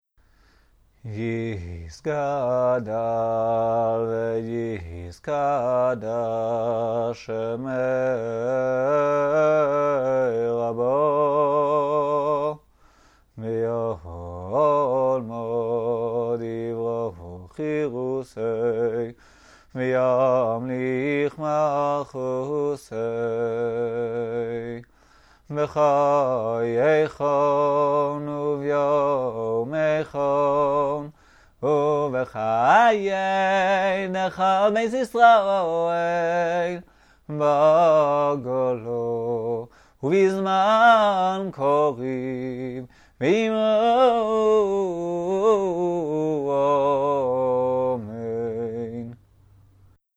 The German custom as practised in K'hal Adas Yeshurun is to sing the Half Kaddish preceding Maftir to a special tune. see